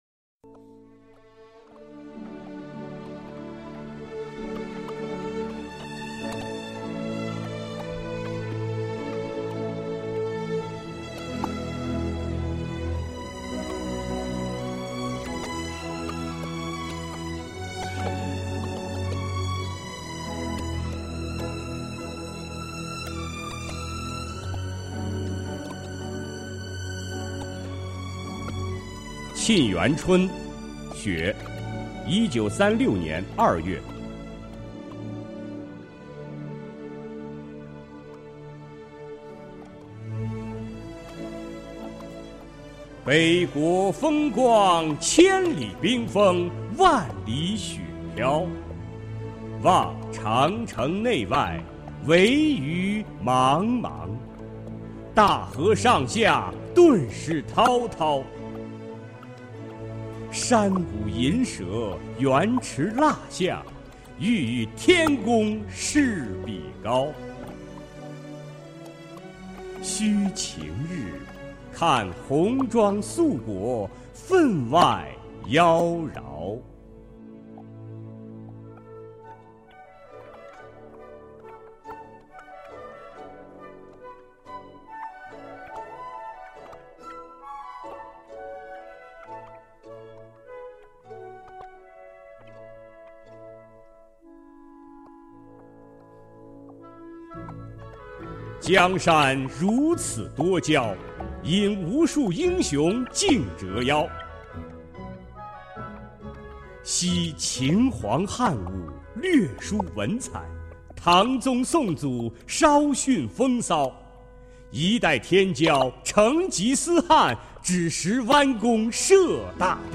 首页 视听 经典朗诵欣赏 毛泽东：崇高优美、超越奇美、豪华精美、风格绝殊